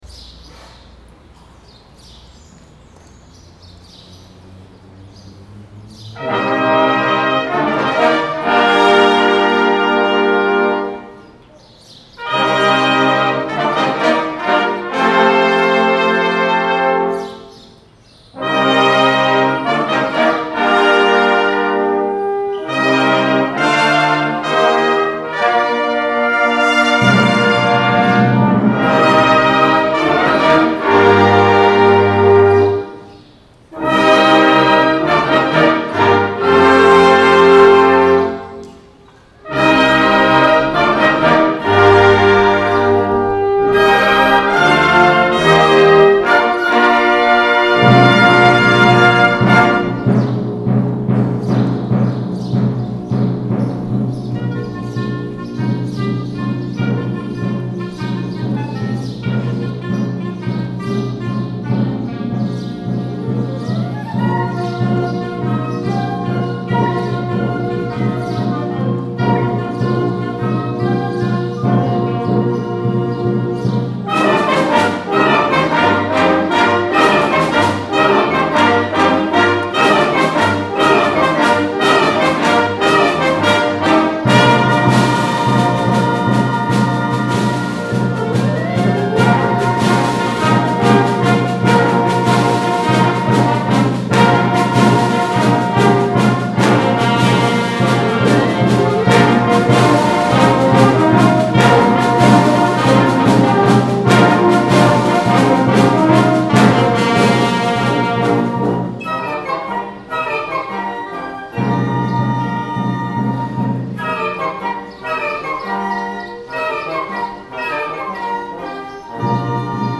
Concert Band
Recorded at the Prince William County Band Festival – May 22, 2022